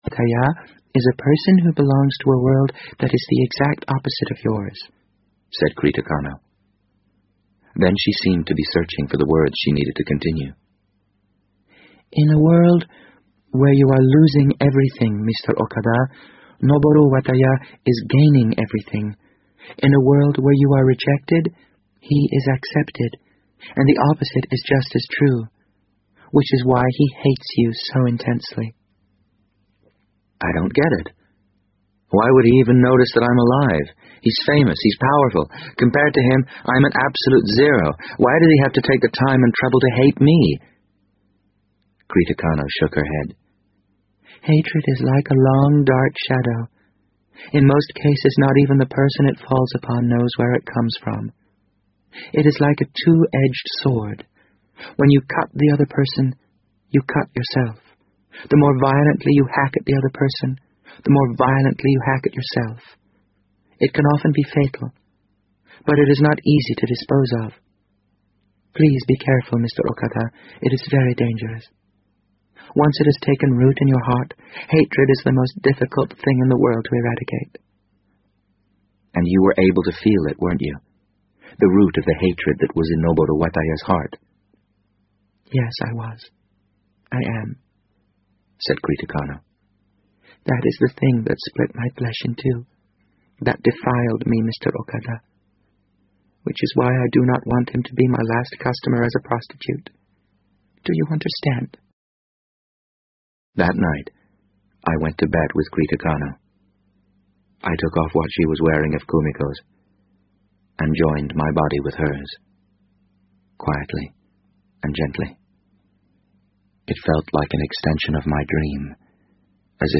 BBC英文广播剧在线听 The Wind Up Bird 008 - 14 听力文件下载—在线英语听力室